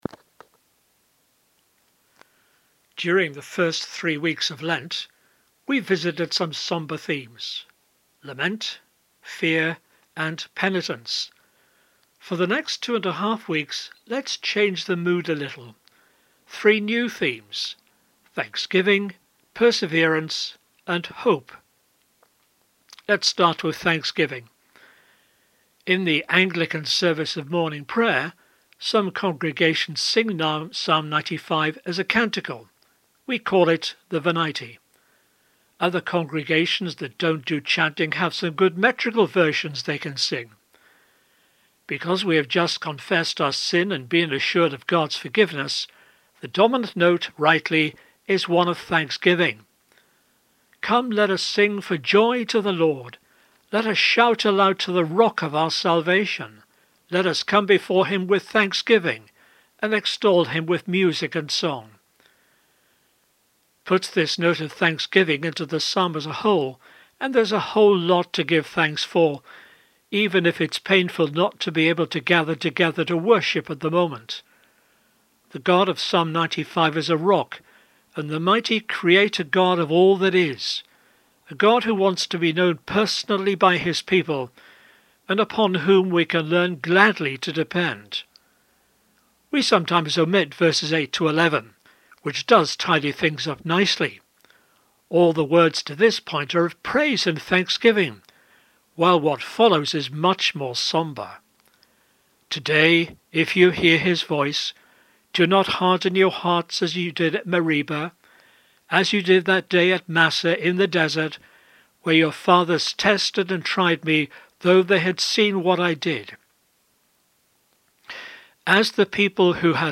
Wednesday 10 March Reflection